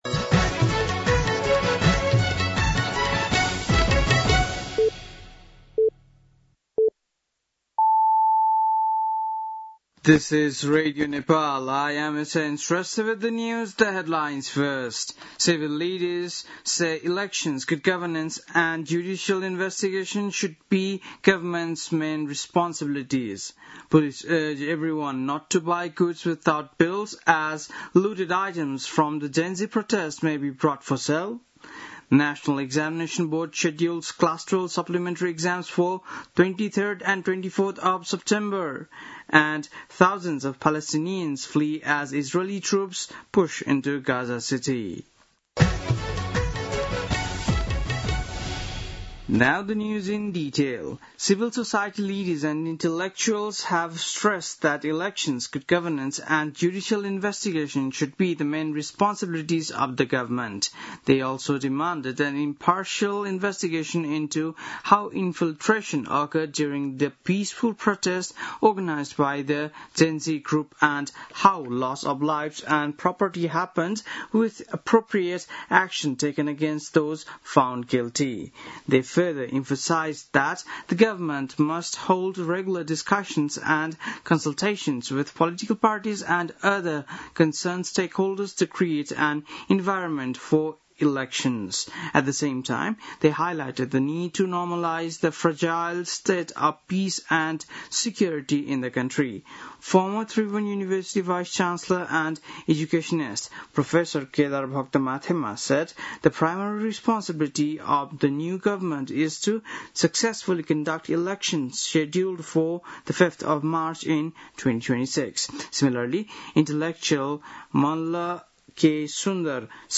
बेलुकी ८ बजेको अङ्ग्रेजी समाचार : १ असोज , २०८२
8-pm-news-6-01.mp3